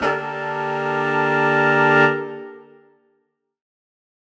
Index of /musicradar/undercover-samples/Horn Swells/D
UC_HornSwell_Dsus4min6.wav